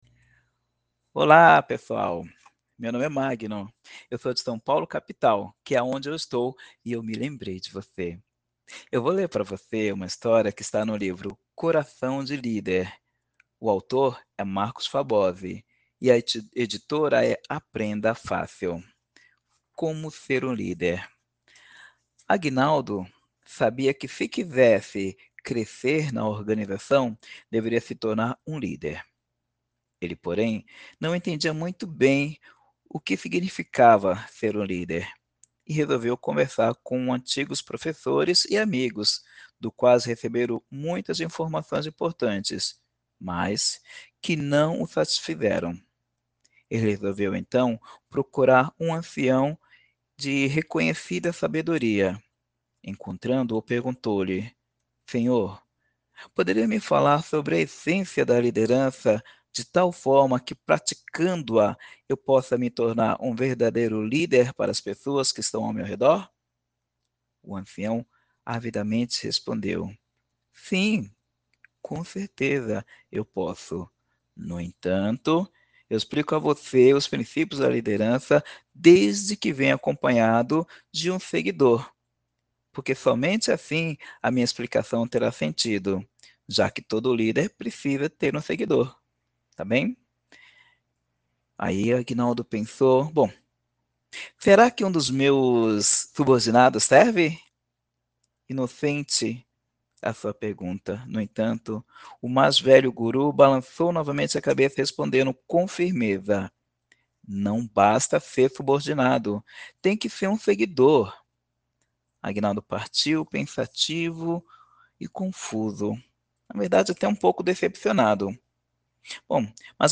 Trecho do livro “Coração de Líder”